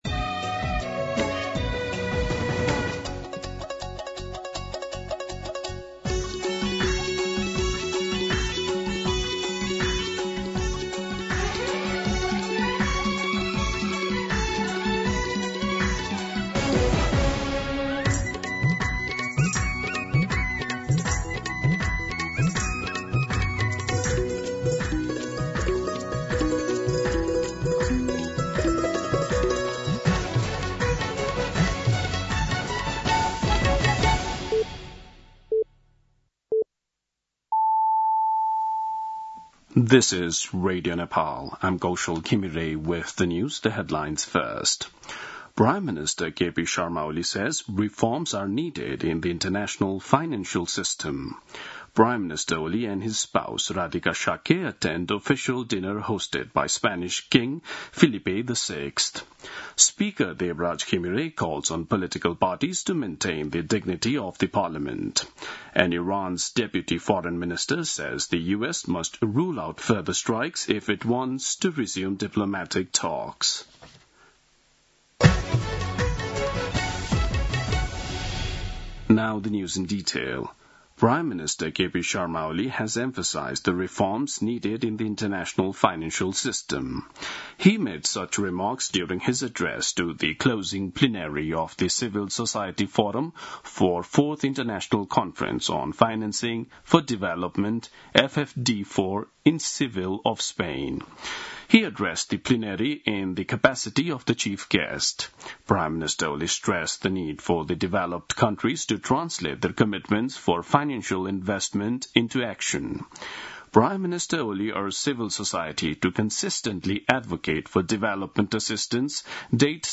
दिउँसो २ बजेको अङ्ग्रेजी समाचार : १६ असार , २०८२